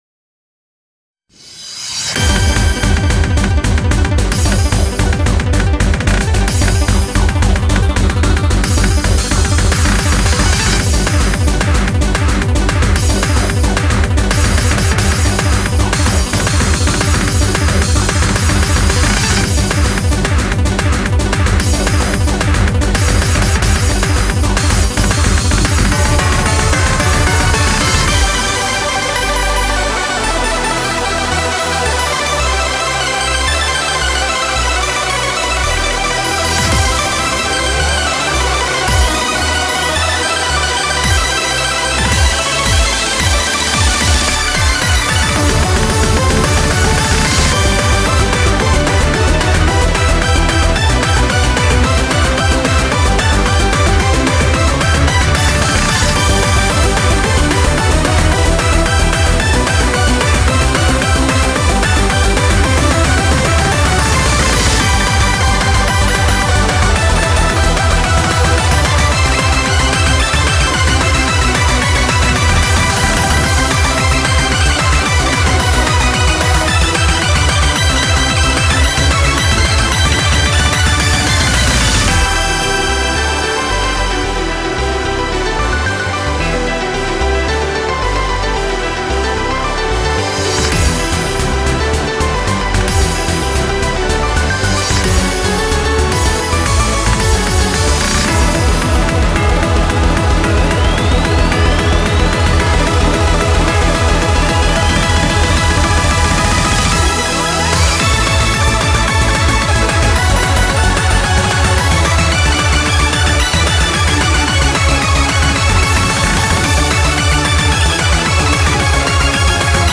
맛이 가서 다운받은 BGM인데 노래가 활기차서 듣고 나면 마음이 평온해지더라고요.